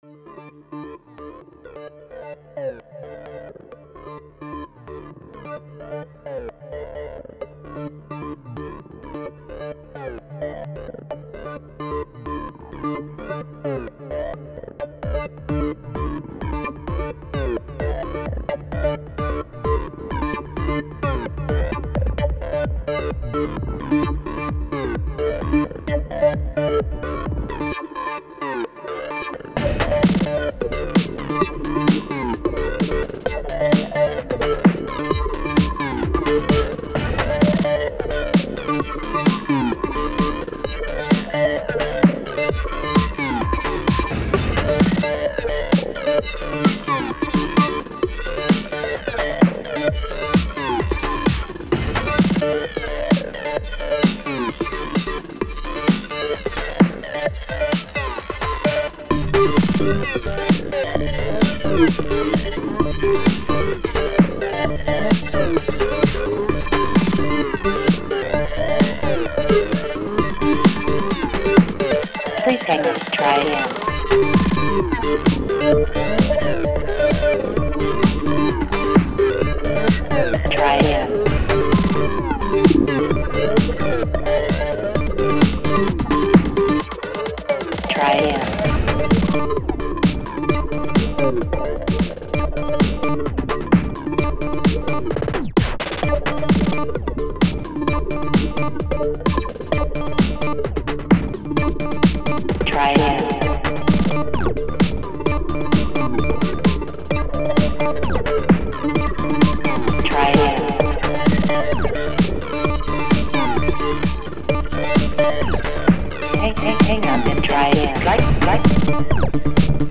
Experimented a lot with resampling to make a decent track.